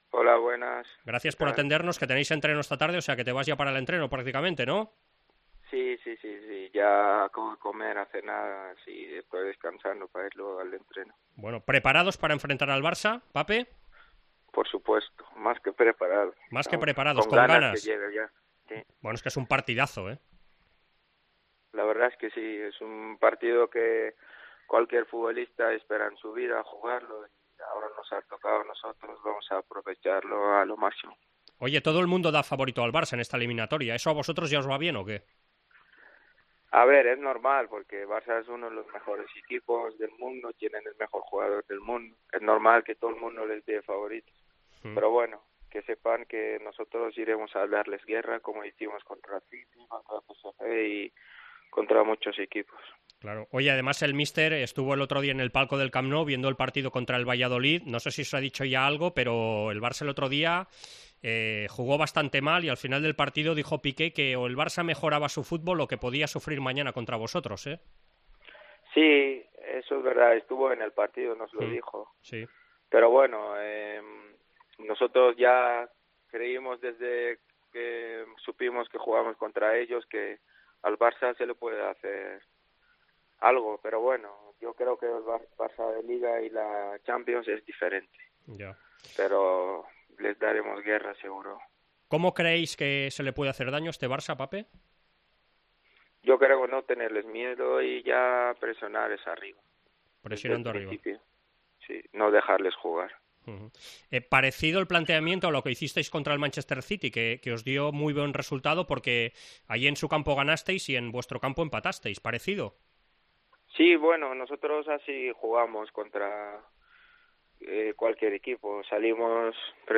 El jugador del Olympique de Lyon Pape Cheickh avisa al Barça en los micrófonos de Esports Cope antes del partido de ida este martes en el Parc Olympique “es normal que todo el mundo dé favorito al Barça porque es uno de los mejores equipos del mundo y tienen al mejor jugador del mundo.